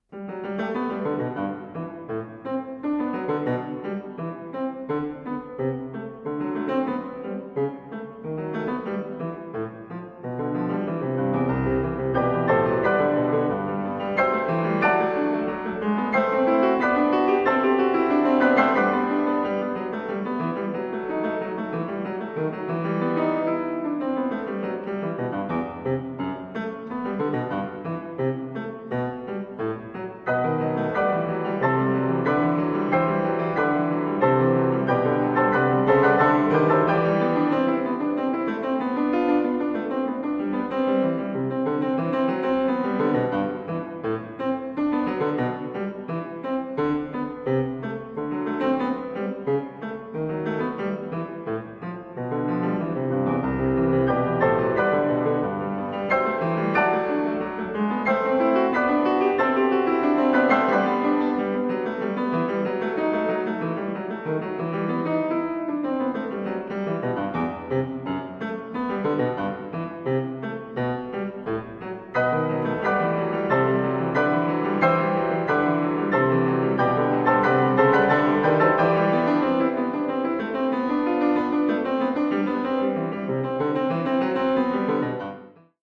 Choraltranskriptionen und Bearbeitungen für 2 Klaviere
Oktober 2016, Lisztzentrum Raiding
Klaviere: Steinway